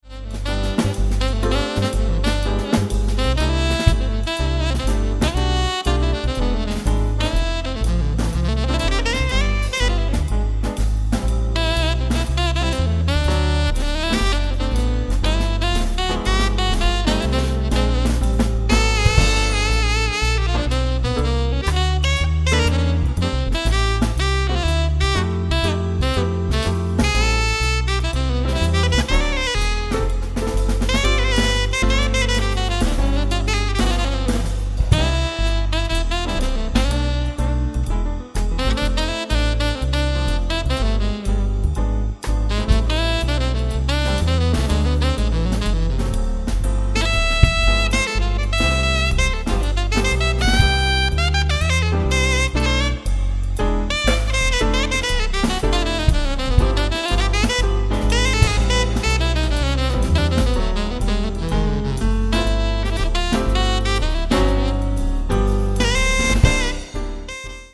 keyboards/left hand bass
drums